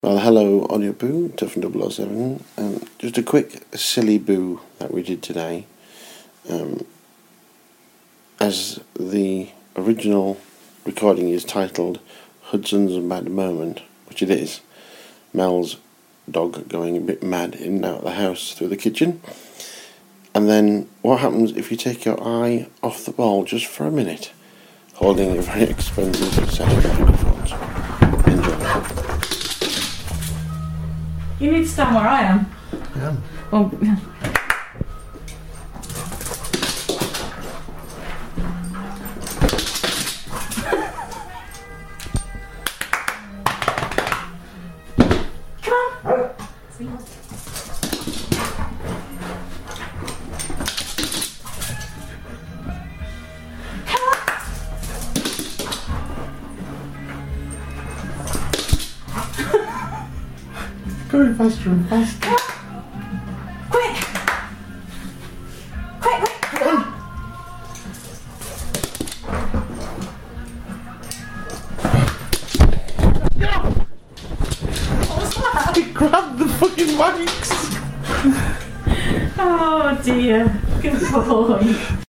A dog's mad moment, but what happens if you take your eye off the ball!